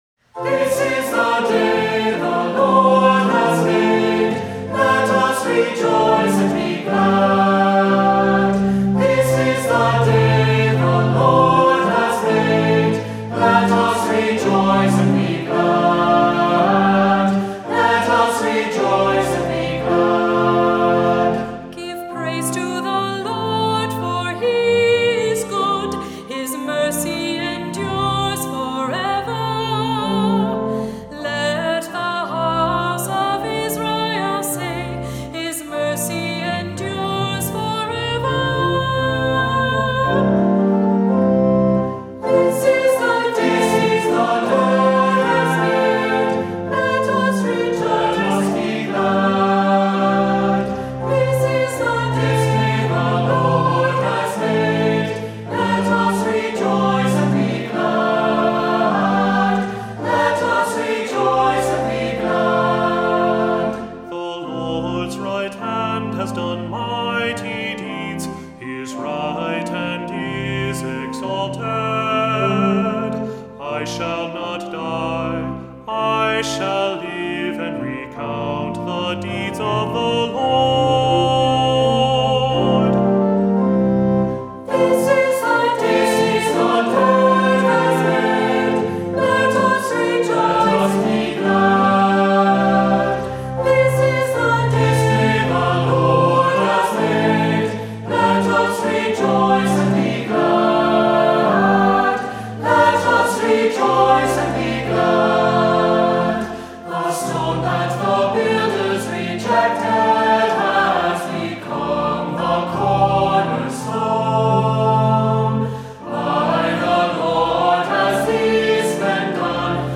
Voicing: 2-part Choir, assembly, cantor